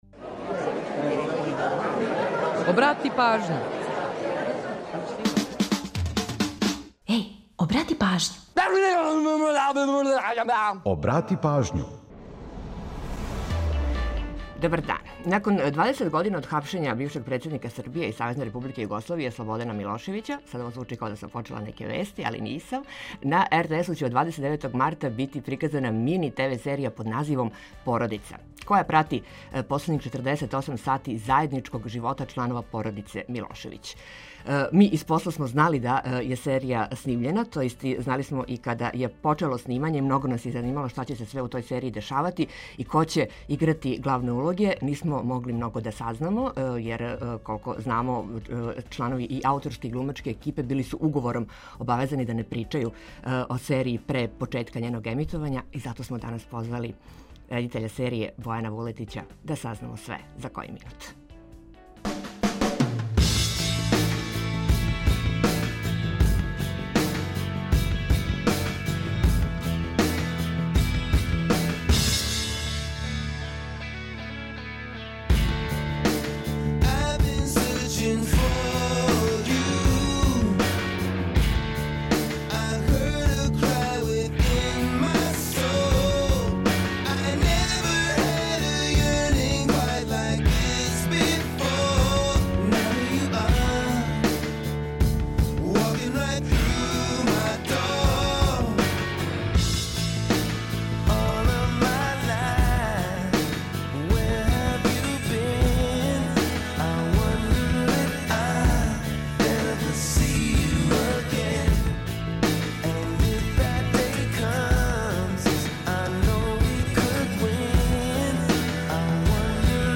Ту је и пола сата резервисаних за домаћицу, музику из Србије и региона, прича о једној песми и низ актуелних занимљивости и важних информација, попут најаве предстојећег дуплог издања Мартовског фестивала документарног и краткометражног филма.